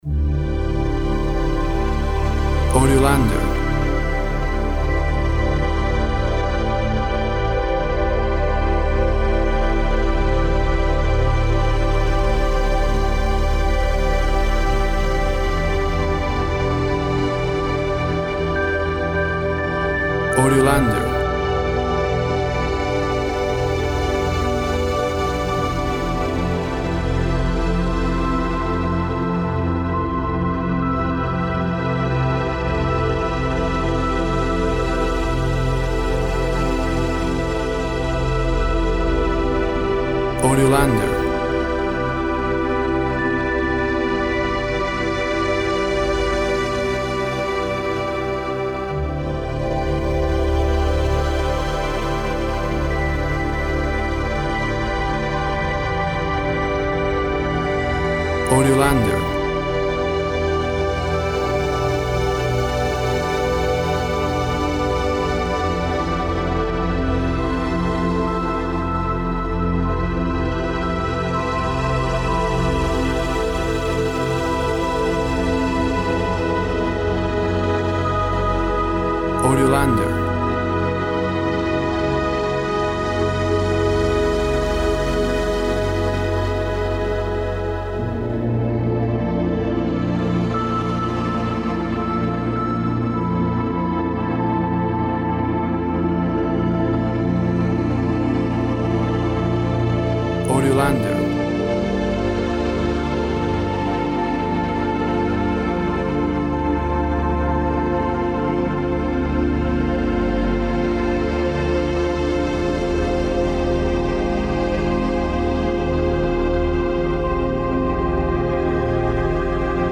Lush, drifting synth strings.
Tempo (BPM) 48